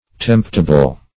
Search Result for " temptable" : Wordnet 3.0 ADJECTIVE (1) 1. susceptible to temptation ; The Collaborative International Dictionary of English v.0.48: Temptable \Tempt"a*ble\, a. Capable of being tempted; liable to be tempted.